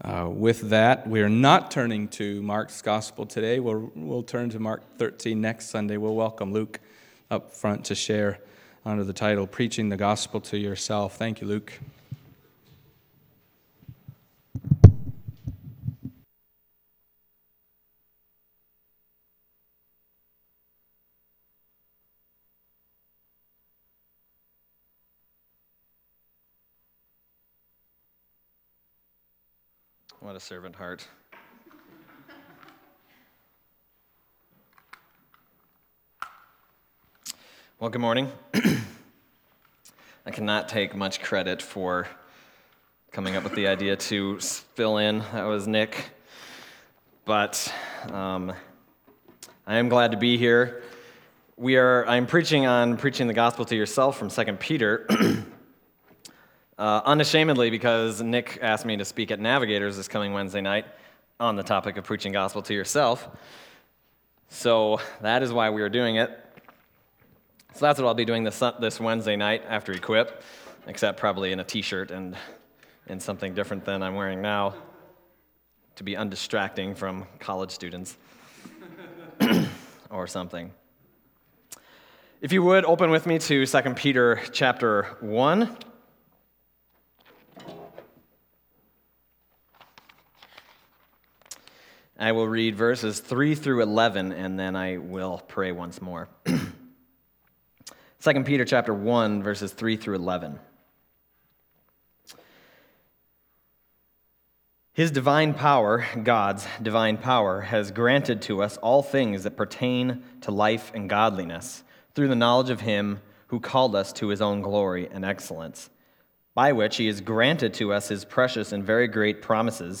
Other Passage: 2 Peter 1:3-11 Service Type: Sunday Morning 2 Peter 1:3-11 « Takers and Givers The Chapter with Three Endings